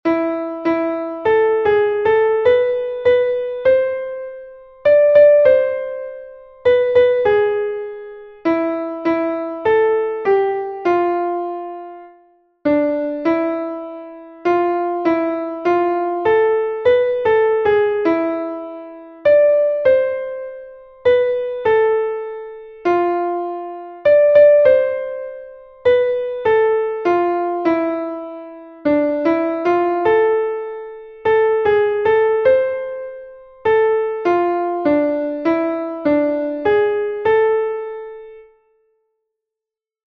Melodic dictation
melodic dictation to identify errors
dictado_melodico_correcto.mp3